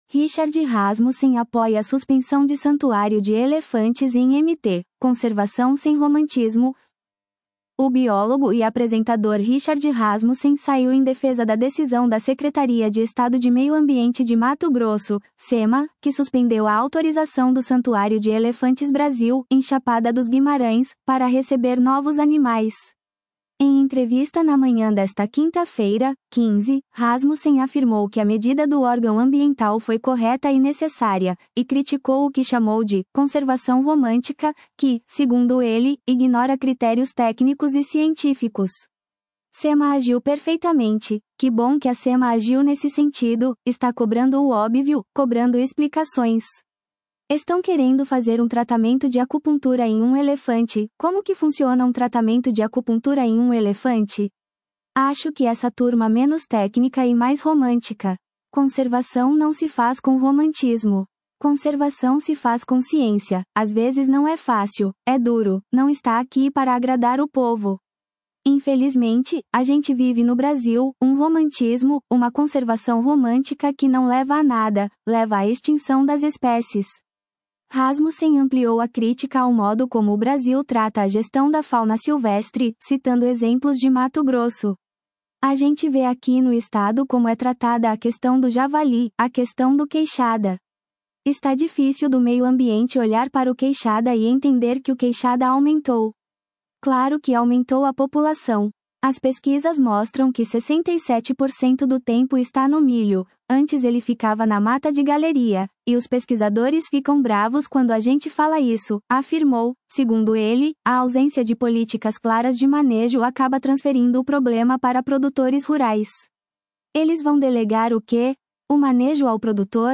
Em entrevista na manhã desta quinta-feira (15), Rasmussen afirmou que a medida do órgão ambiental foi correta e necessária, e criticou o que chamou de “conservação romântica”, que, segundo ele, ignora critérios técnicos e científicos.